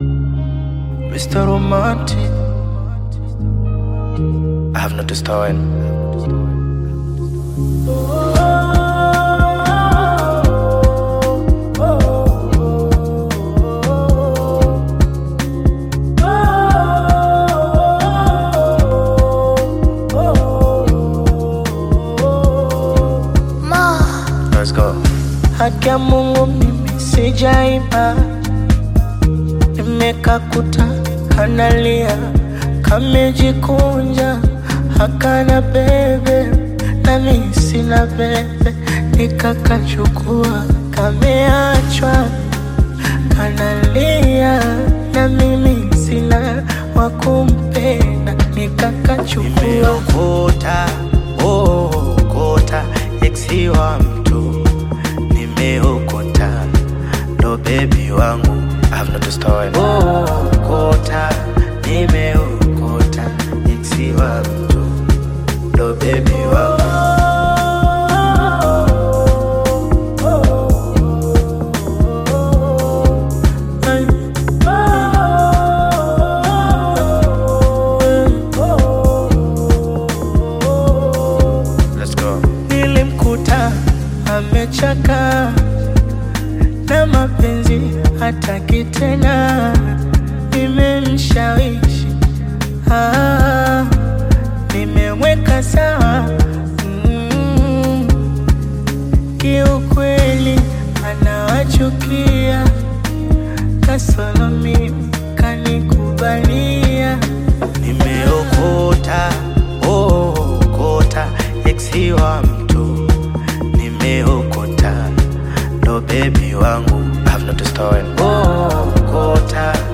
Bongo Flava
Tanzanian Bongo Flava artist, singer, and songwriter